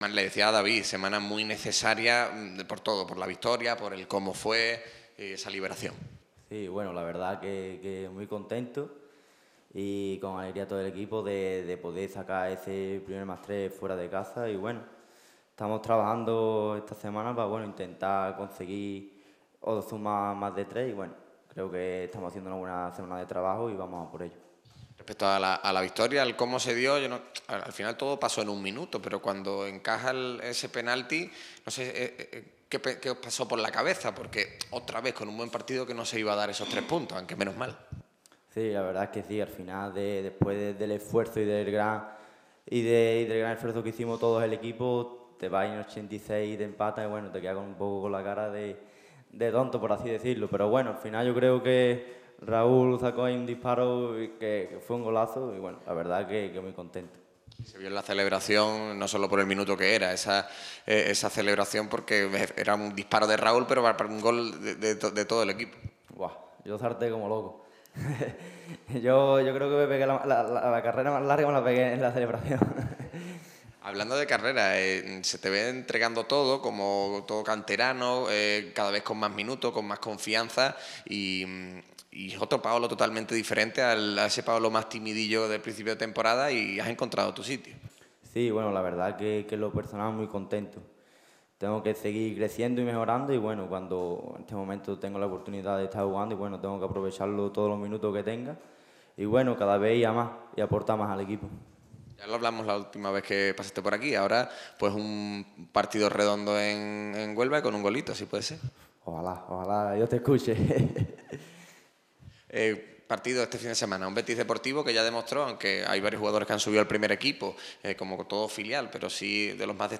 Rueda de prensa íntegra